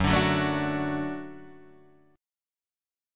F#m#5 chord